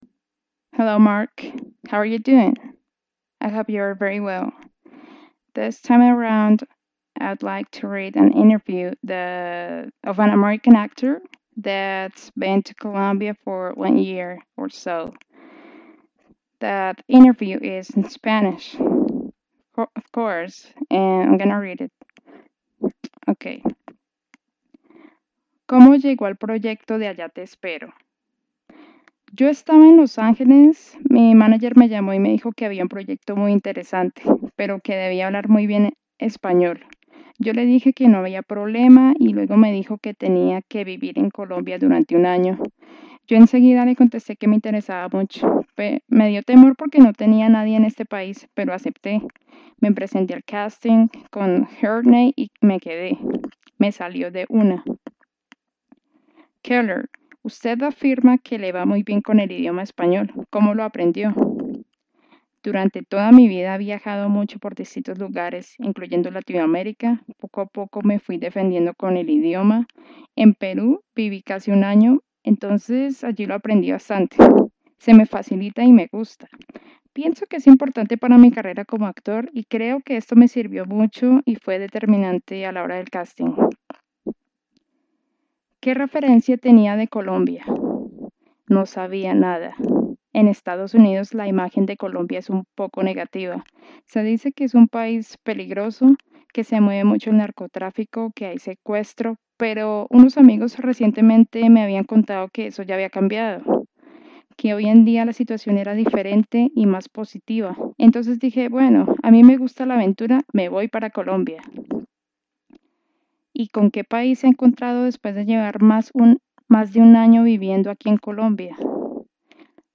An interview in spanish of an American actor in Colombia. I put a spoken twist on it